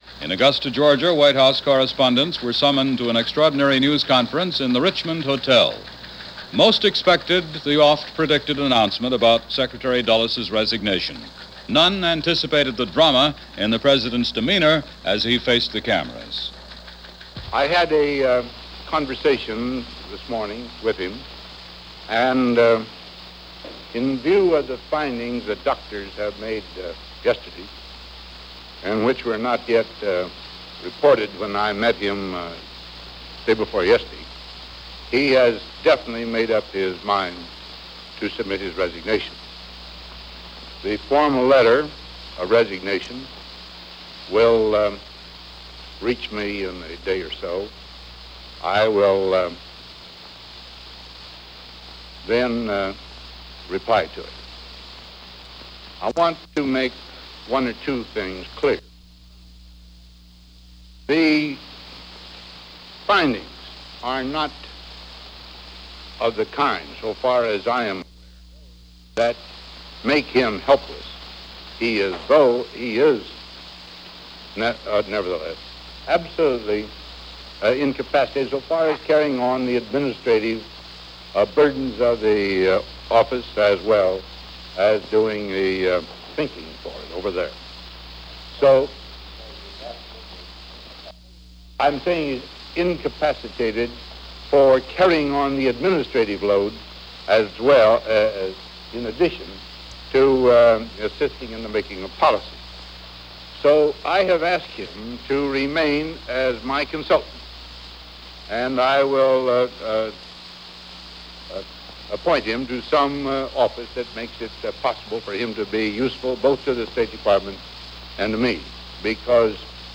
April 15-17, 1959 - Resignation Of John Foster Dulles - news and commentary on the resignation of Secretary of State Dulles and reactions.